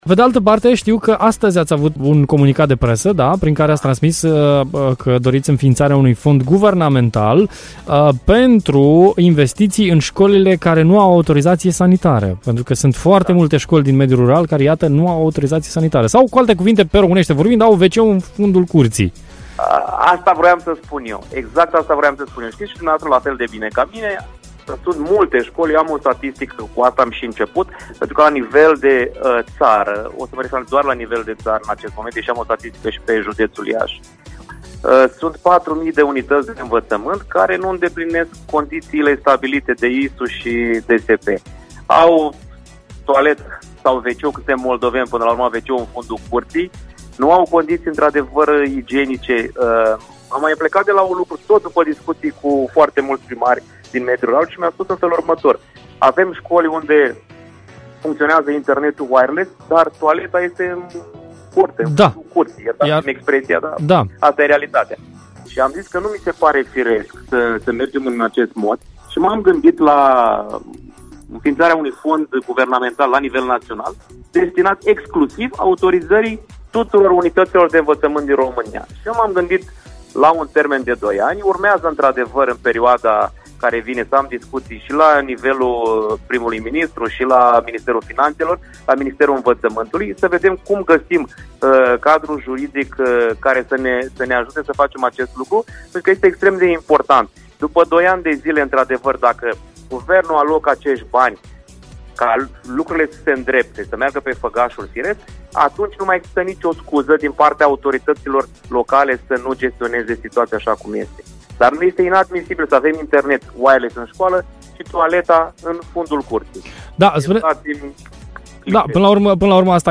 Mai multe detalii despre acest fond de investiții am aflat chiar de la Silviu Macovei, în direct la Radio Hit: